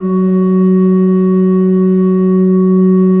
Index of /90_sSampleCDs/Propeller Island - Cathedral Organ/Partition L/ROHRFLUTE MR